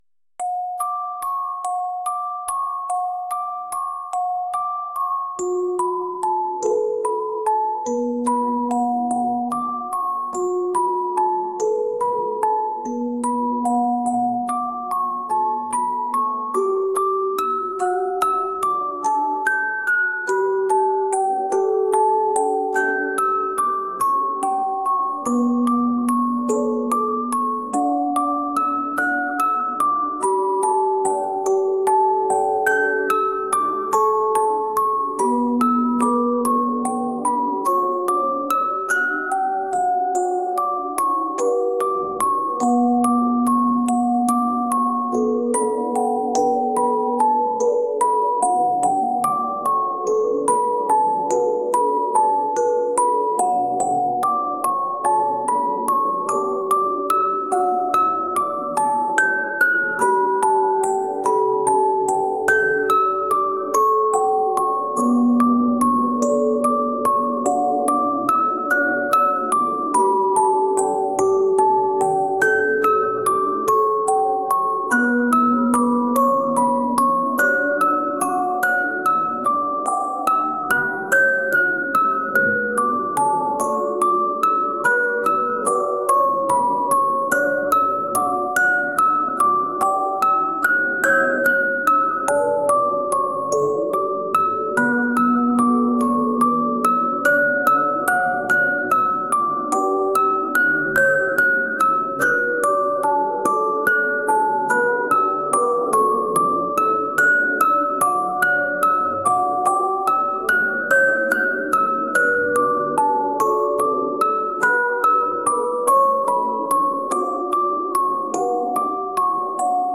オルゴール